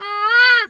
bird_peacock_squawk_soft_06.wav